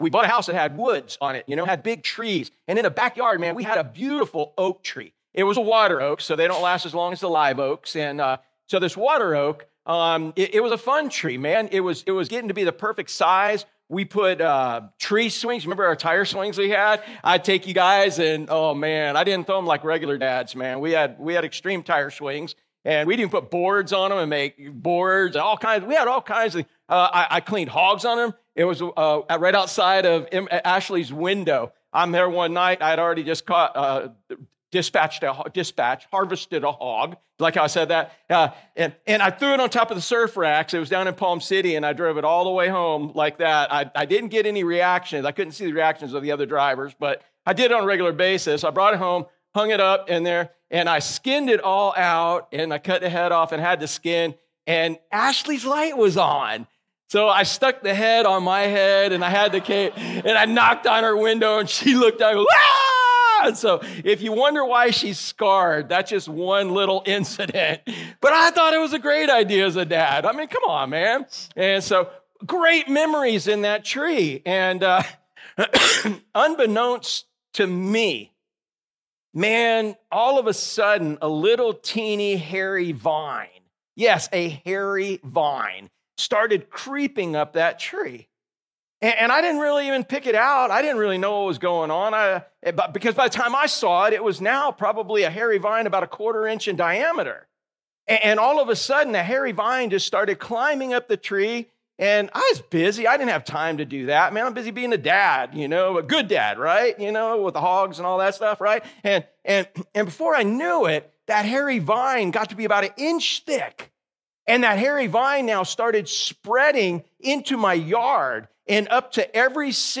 Sermons | Driftwood Church at the Beach